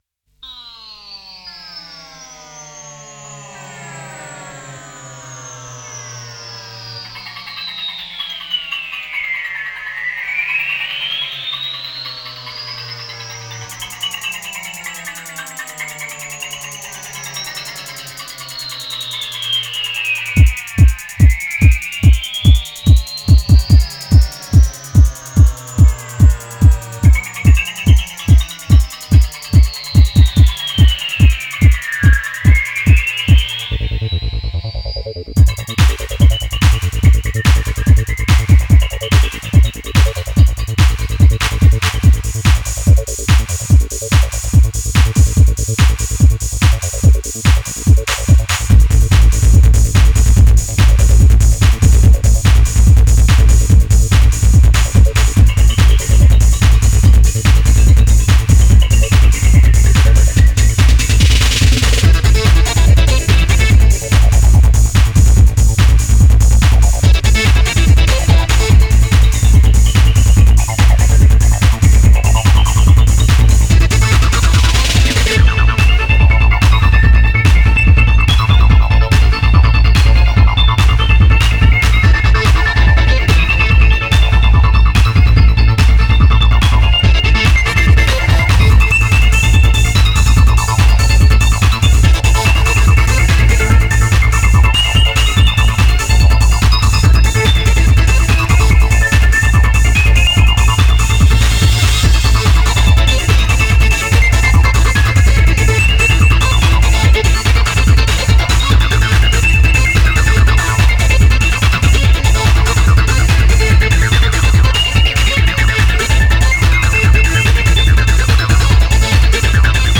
Файл в обменнике2 Myзыкa->Psy-trance, Full-on
Style: Psy-Trance, Goa